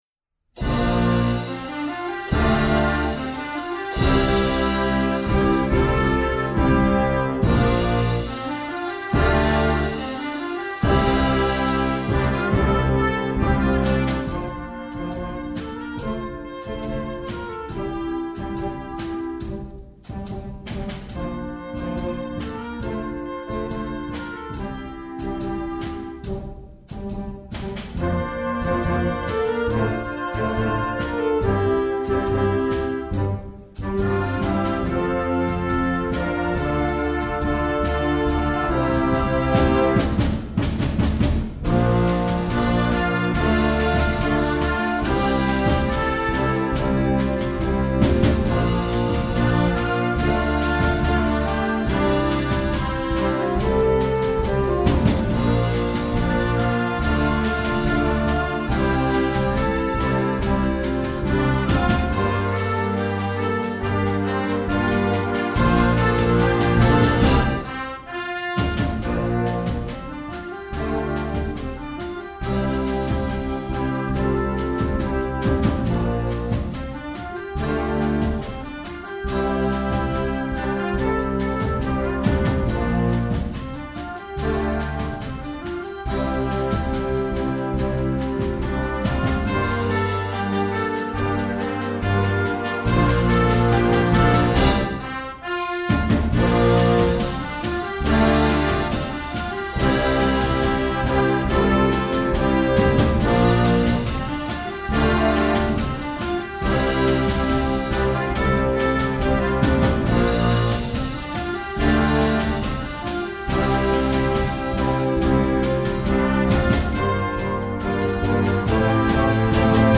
Instrumentation: concert band
pop